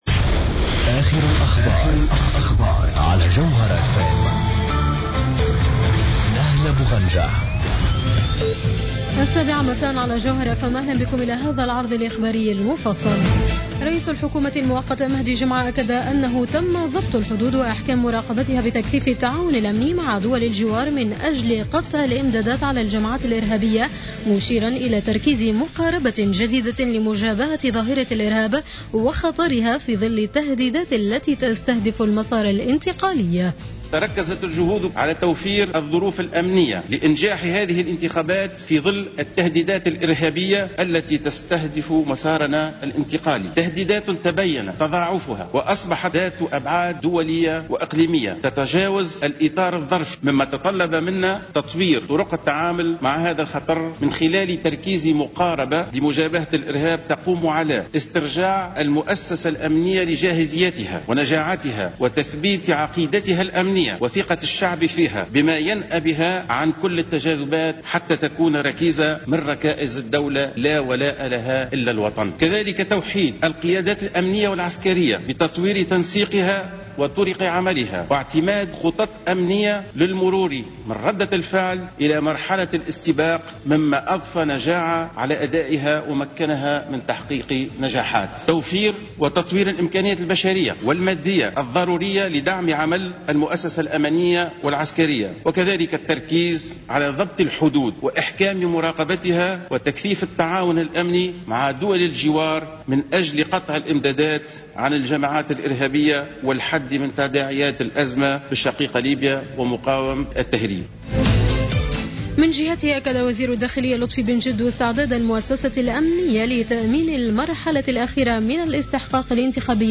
نشرة أخبار السابعة مساء ليوم الأربعاء 10-12-14